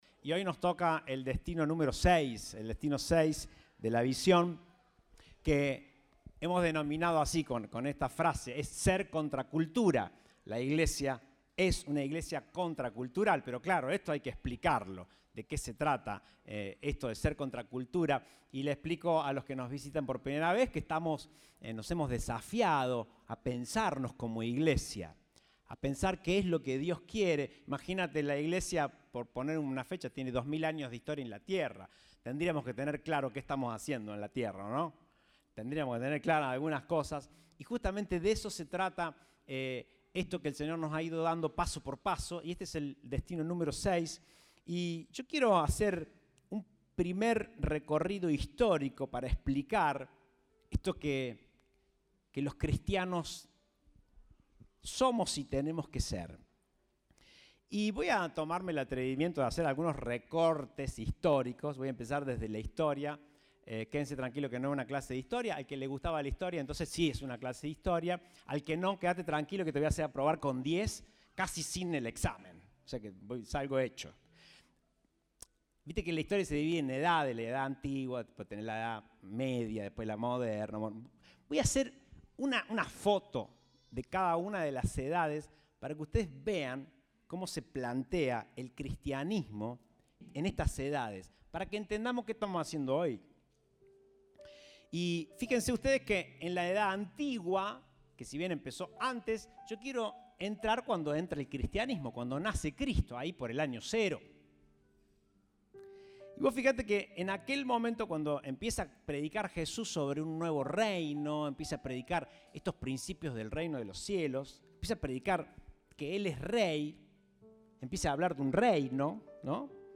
Compartimos el mensaje del Domingo 15 de Mayo de 2022.